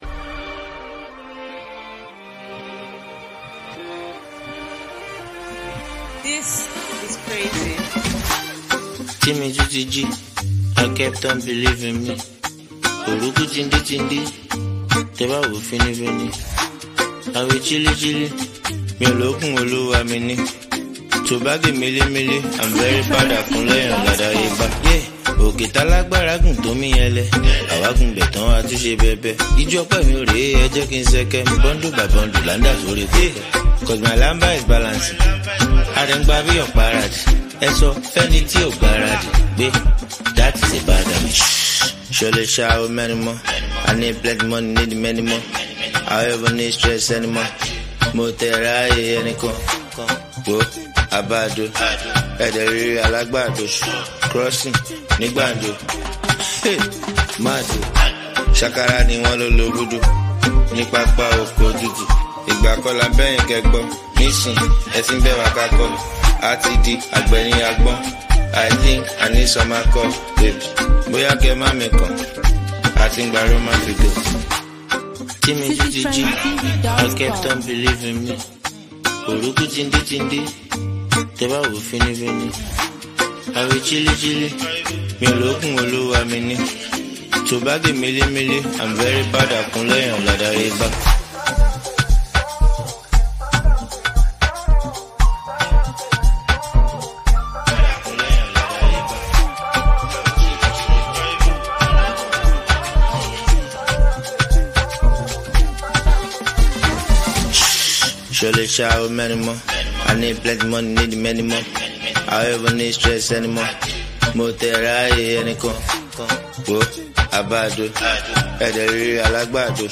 laid-back and vibey track
Known for his easy-going delivery and catchy hooks